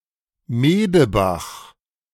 Medebach (German: [ˈmeːdəˌbax]